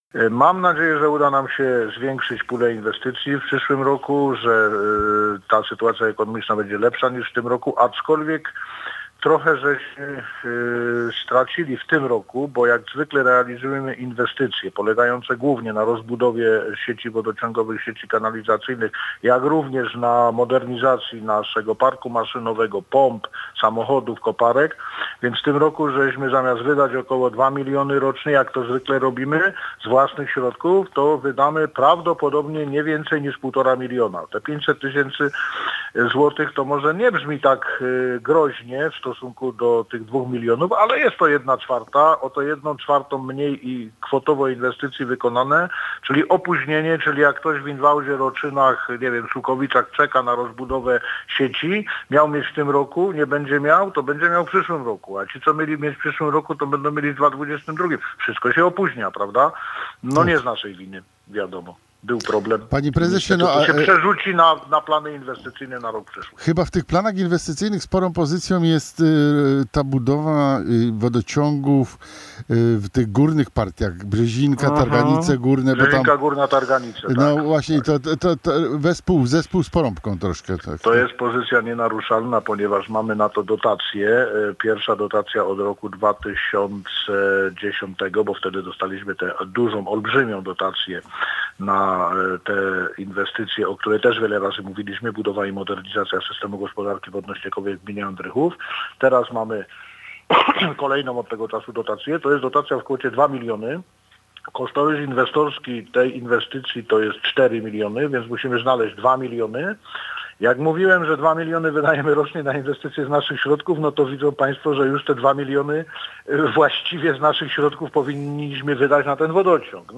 Audycja w Radio Andrychów.
Audycja w Radio Andrychów z dnia 29.12.2020